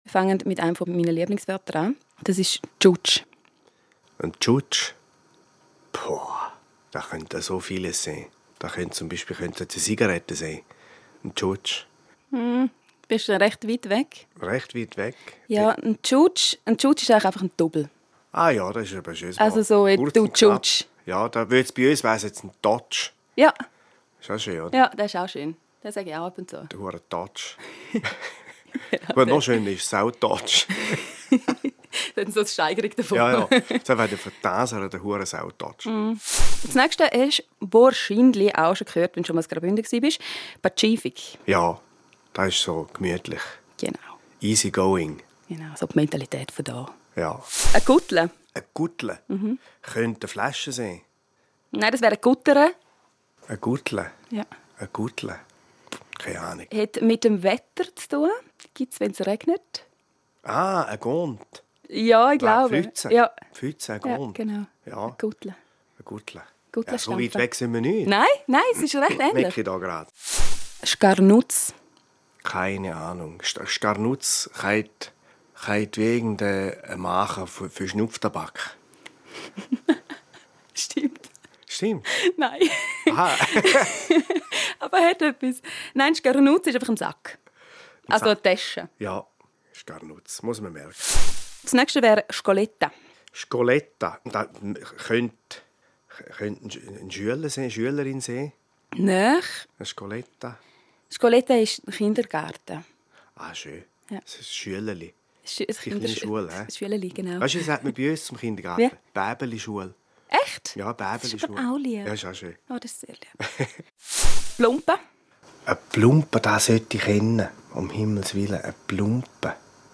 Sendungs- Beitrag Simon Enzler errät Bündnder Wörter.MP3